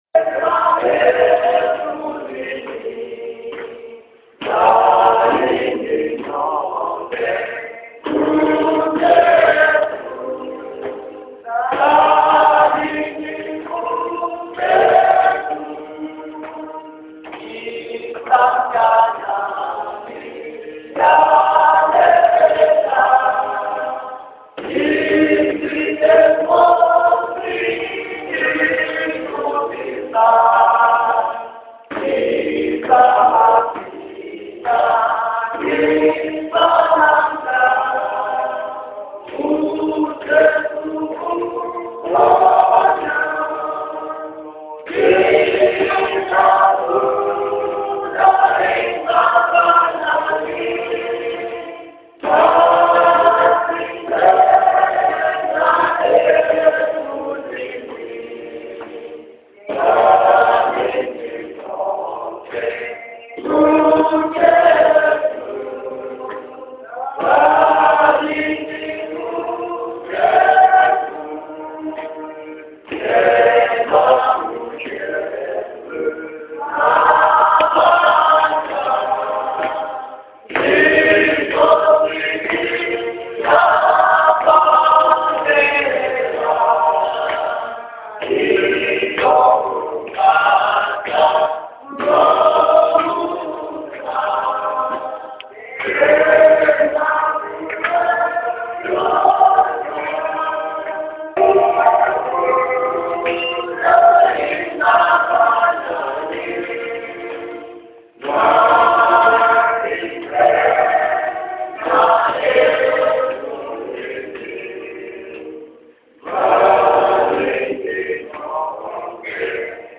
Listen here to the congregation in Nhlangano singing this popular favourite of the Lutheran Church: